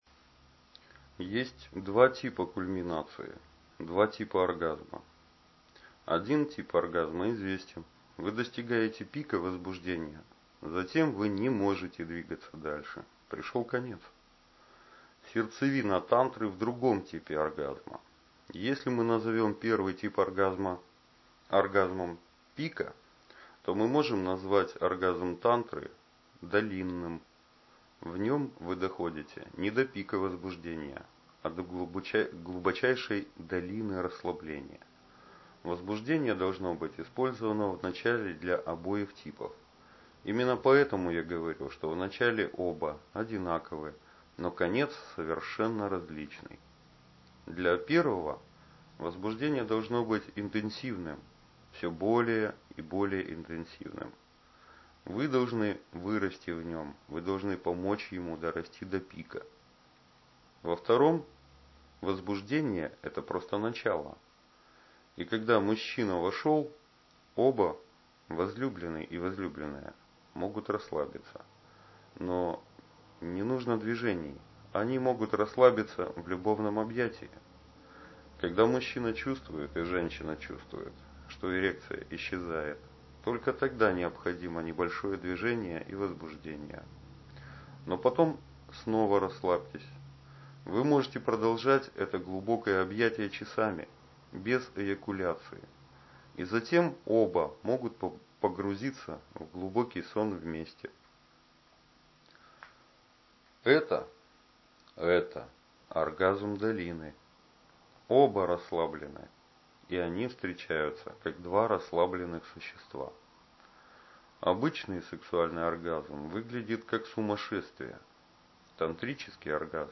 Аудиокнига: О сексе